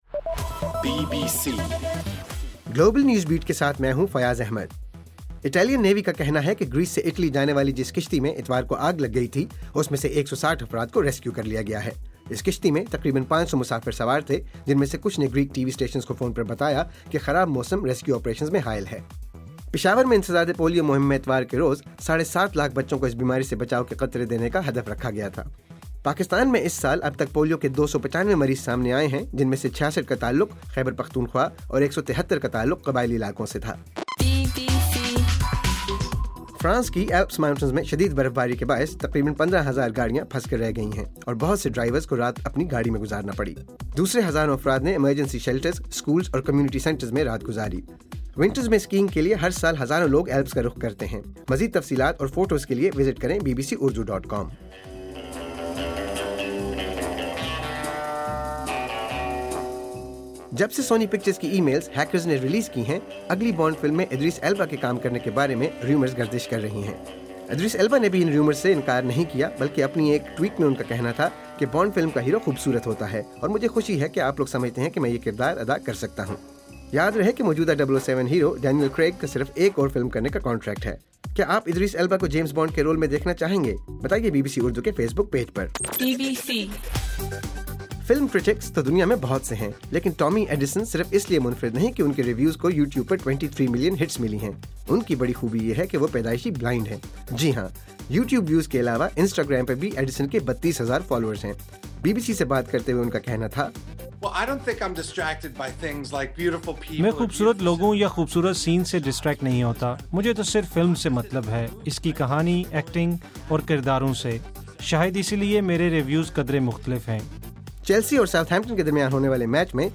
دسمبر 29: صبح 1 بجے کا گلوبل نیوز بیٹ بُلیٹن